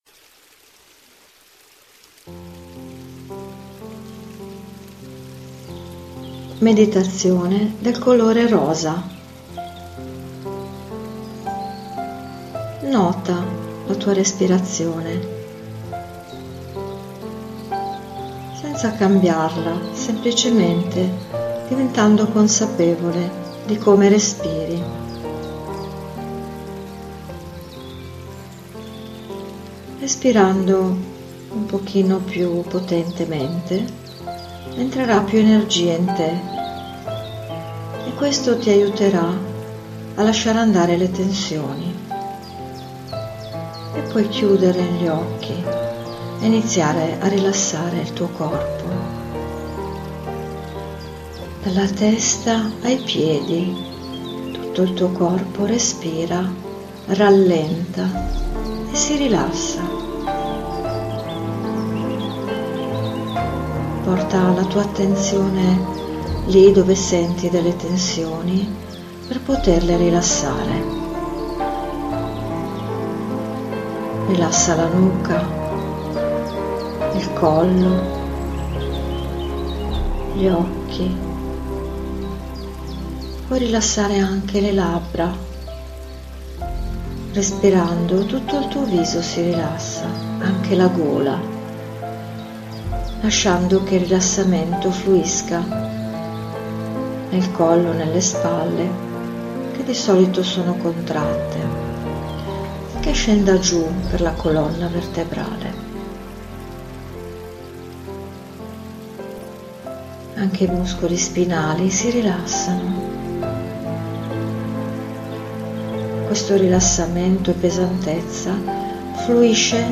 Meditazione guidata del colore rosa - Scarica Gratis MP3
Una meditazione guidata, pratica ed efficace, utilizzabile in ogni momento della giornata. Un aiuto concreto per il tuo spirito.